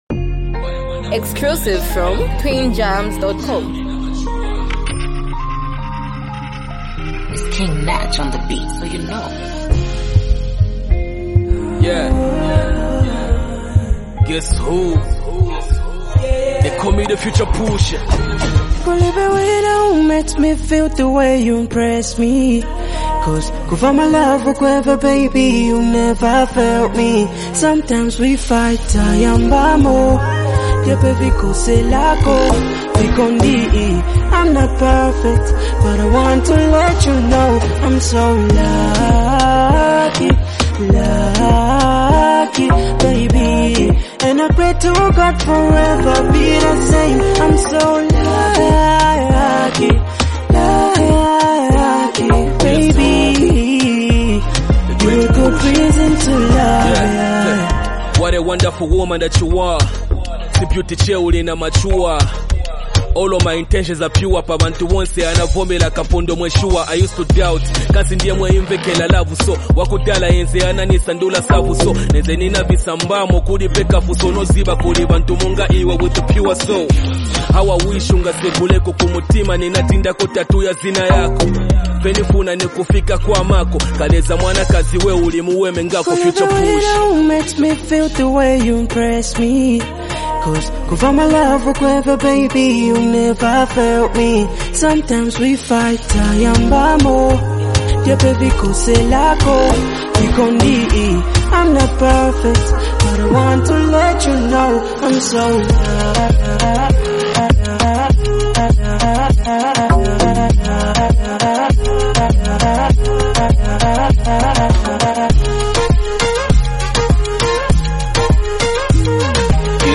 with a melodic and heartfelt contribution